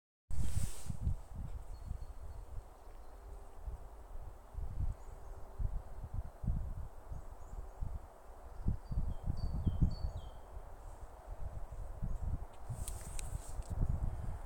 Great Tit, Parus major
Administratīvā teritorijaSalacgrīvas novads
StatusVoice, calls heard